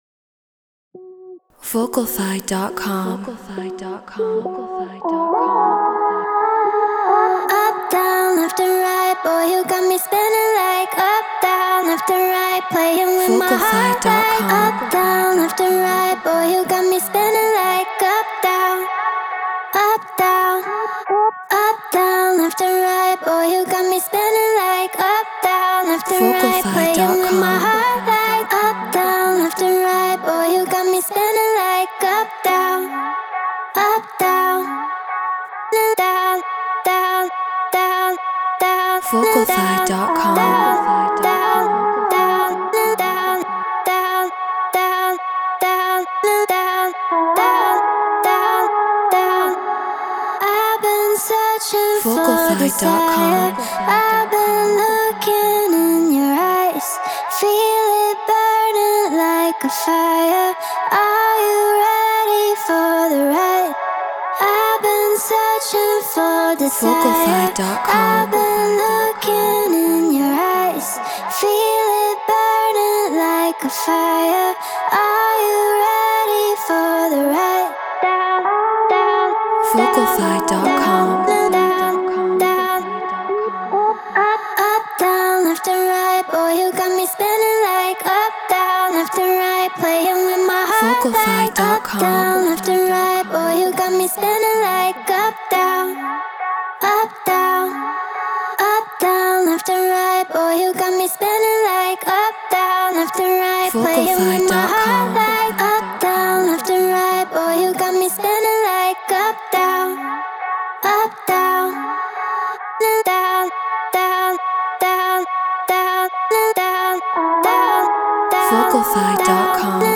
UKG 137 BPM F#min
Shure KSM 44 Apollo Twin X Pro Tools Treated Room